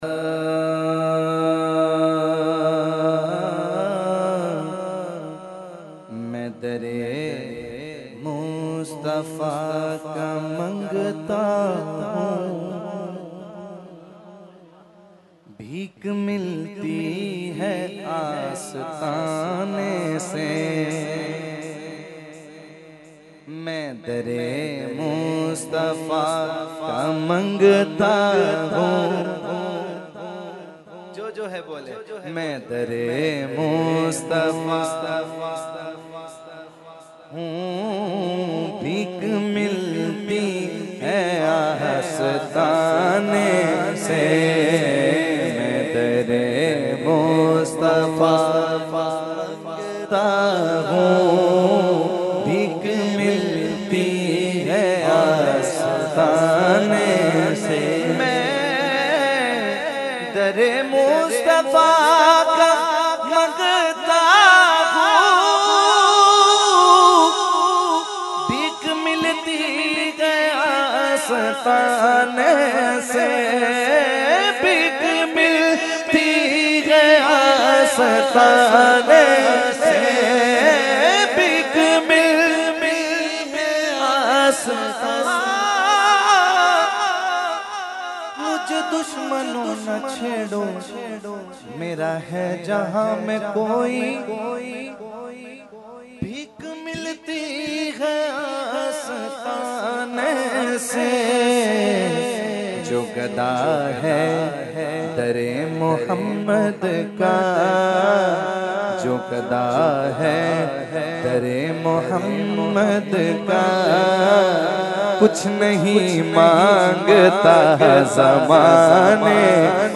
Category : Naat | Language : UrduEvent : Urs Ashraful Mashaikh 2019